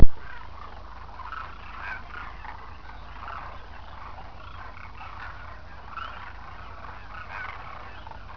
Two cranes fly over a lake
Villages outside Jodhpur, Rajasthan, India